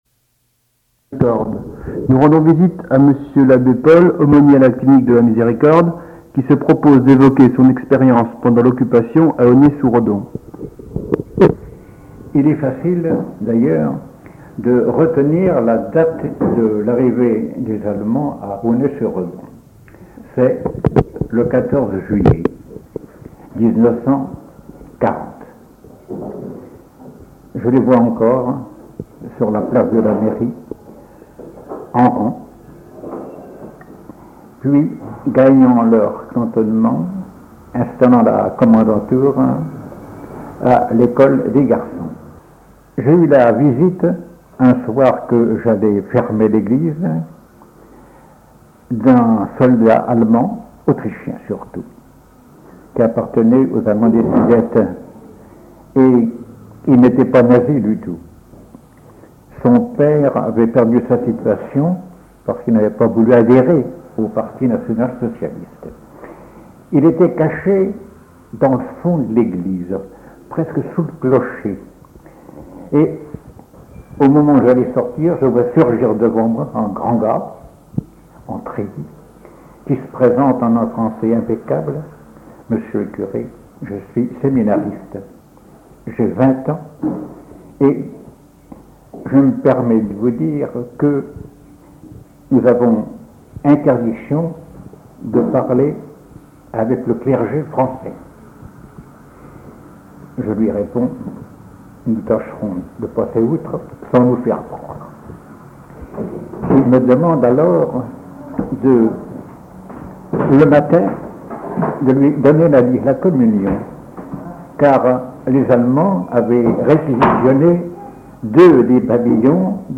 Cette interview est issue du fonds d'enregistrements de témoignages oraux relatifs à la Seconde Guerre mondiale conservés et archivés au Mémorial de Caen, que le musée a gracieusement mis à la disposition de la Maison de la Recherche en Sciences Humaines dans le cadre du projet de recherche Mémoires de Guerre.